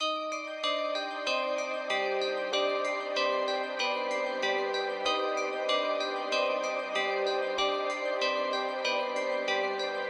冰冻的陷阱钟声
描述：陷阱钟声。
标签： 95 bpm Trap Loops Bells Loops 1.70 MB wav Key : Unknown
声道立体声